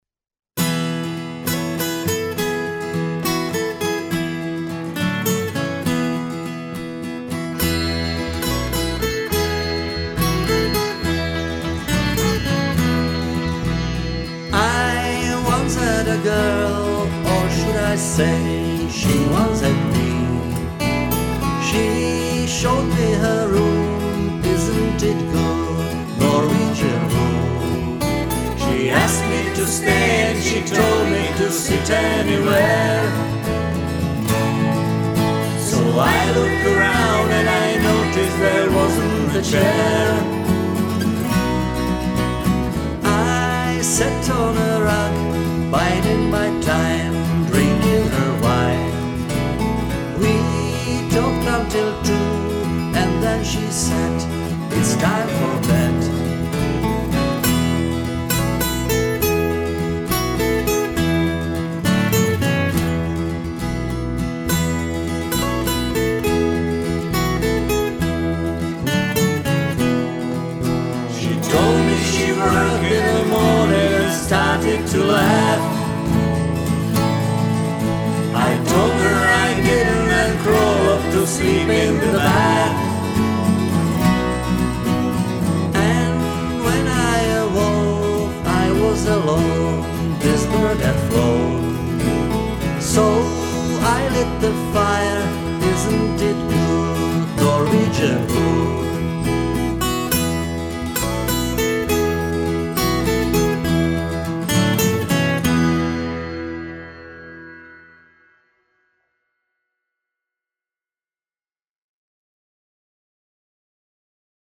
Nahrávalo se dost svérázně tím, co měl kdo doma.
No ale basa je tam fakt málo. grinning